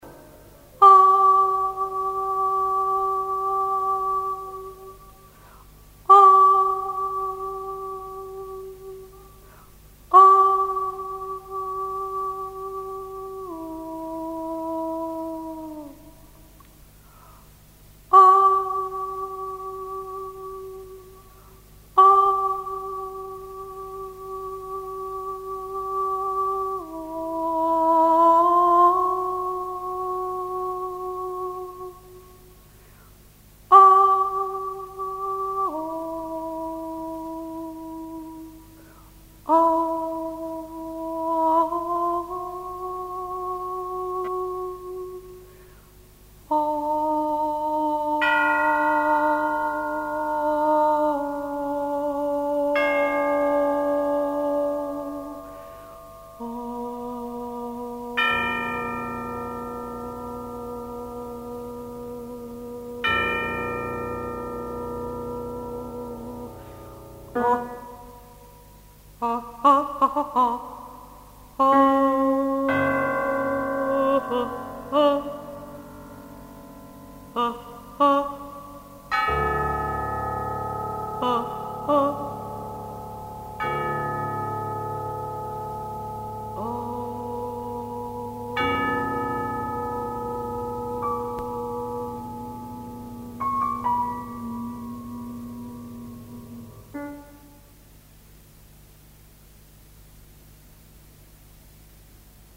Режим: Stereo